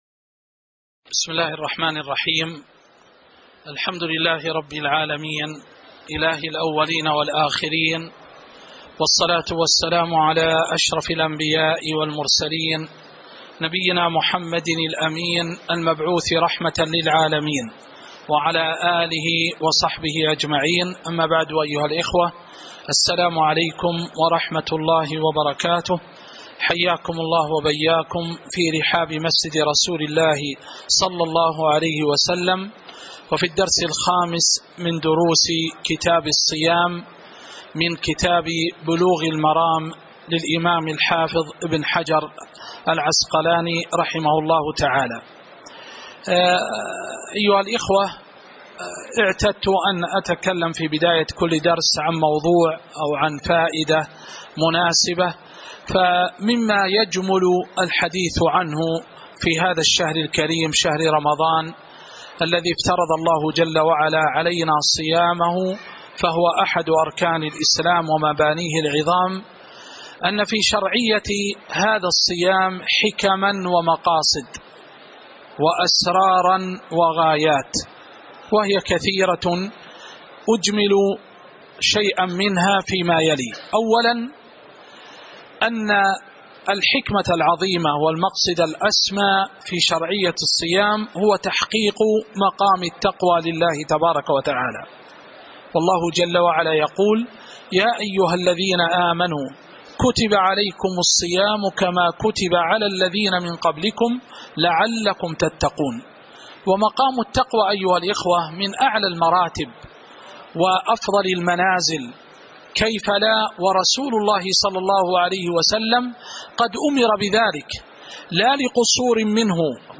تاريخ النشر ٤ رمضان ١٤٤٤ هـ المكان: المسجد النبوي الشيخ